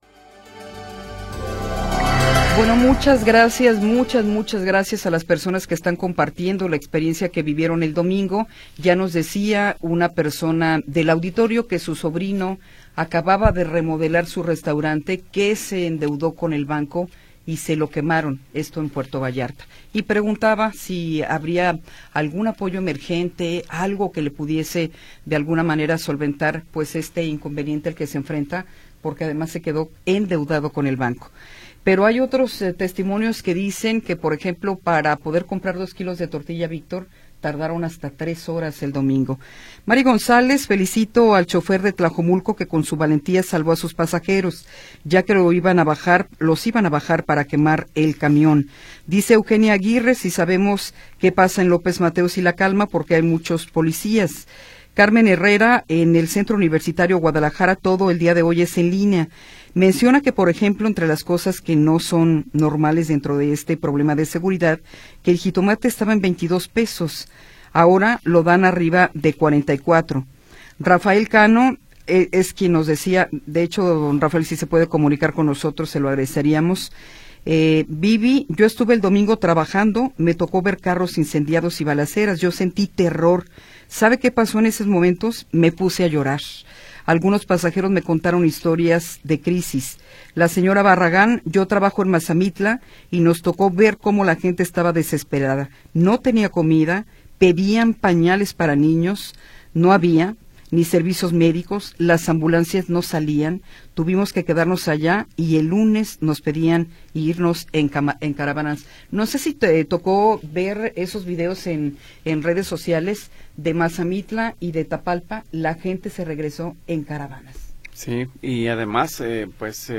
Tercera hora del programa transmitido el 25 de Febrero de 2026.